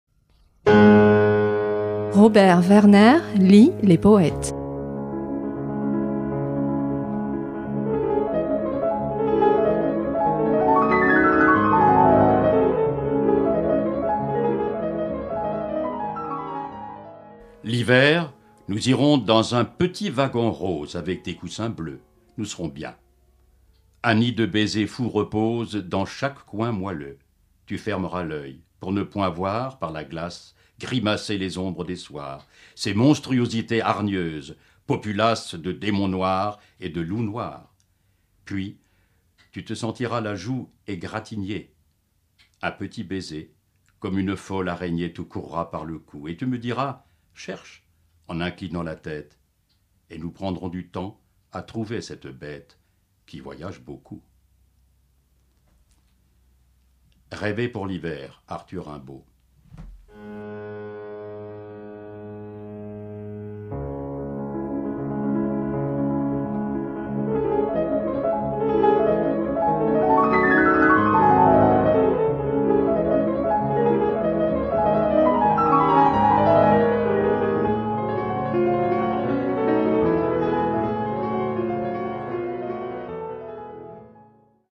lit, cette semaine, Rêvé pour l’hiver, un court sonnet par lequel le jeune Arthur Rimbaud - il n’a alors que 15 ans - célèbre avec élégance l’éveil sentimental à travers un songe délicatement teinté d’érotisme.